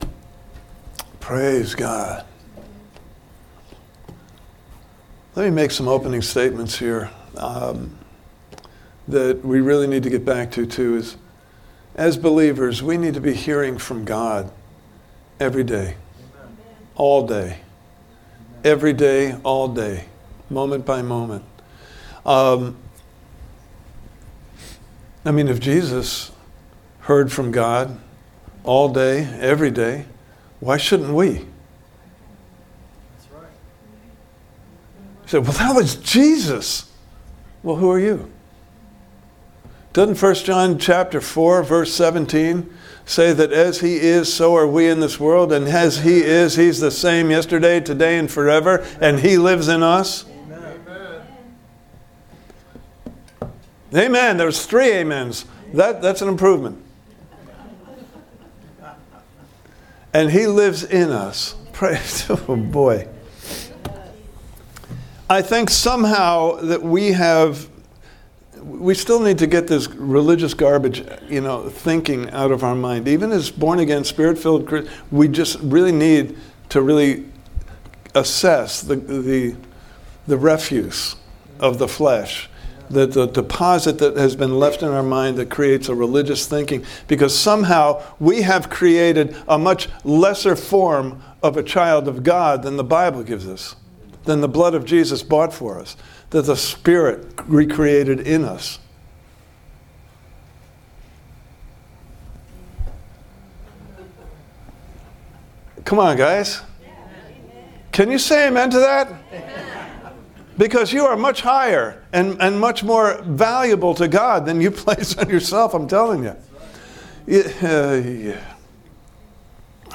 Service Type: Sunday Morning Service « Part 2: An Enemy Has Done This!